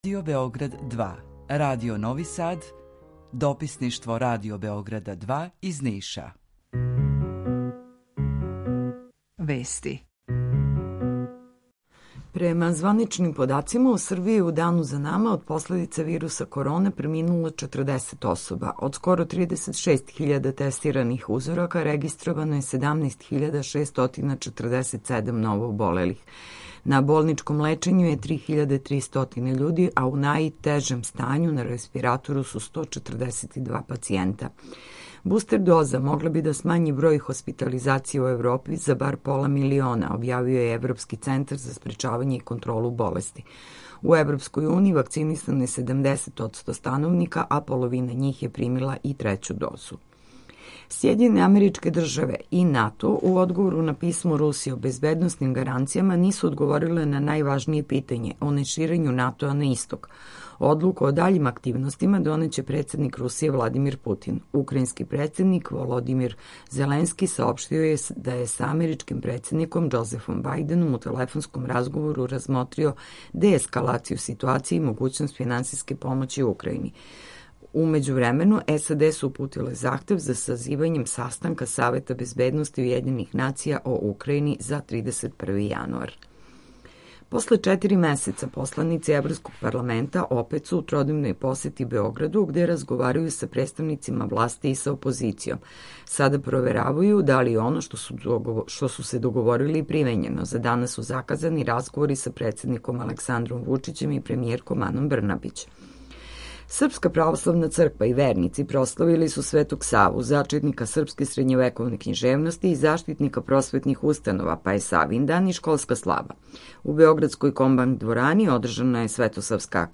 Емисију реализујемо заједно са студијом Радија Републике Српске у Бањалуци и Радијом Нови Сад
Јутарњи програм из три студија